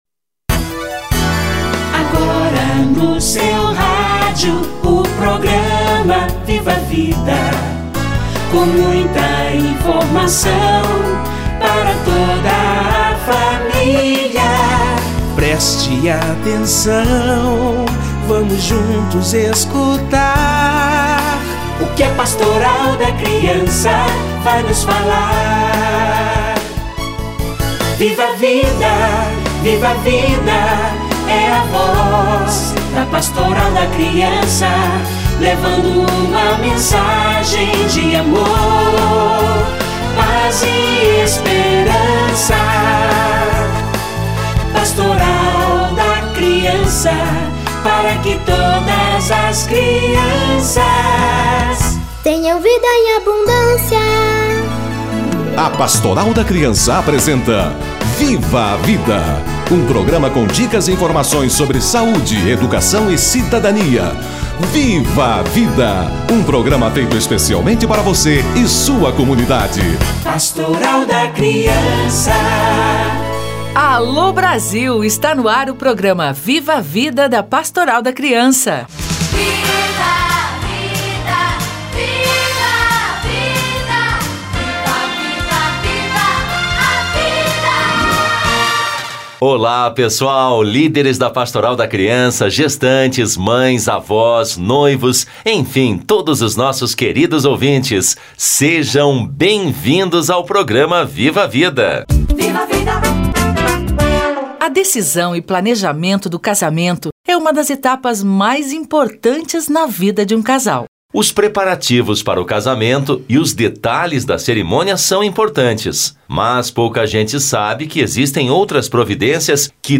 Preparação da mulher para gestação - Entrevista